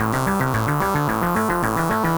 Index of /musicradar/8-bit-bonanza-samples/FM Arp Loops
CS_FMArp A_110-A.wav